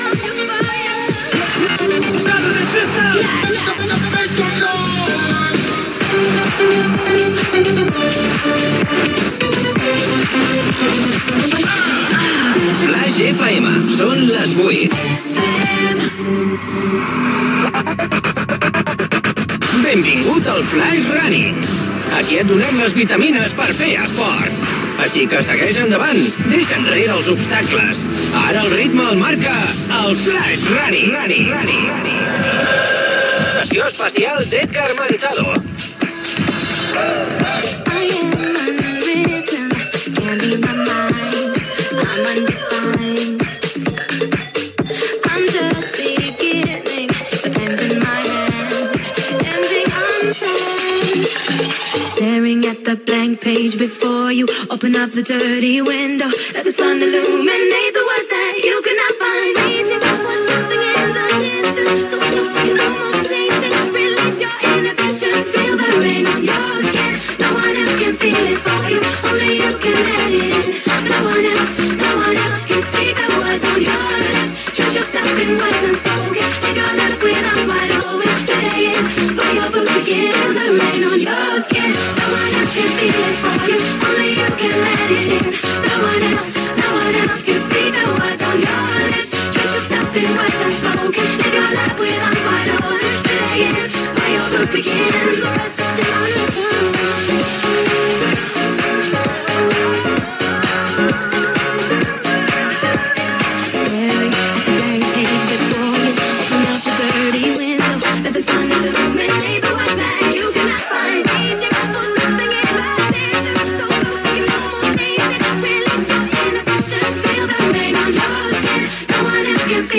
Musical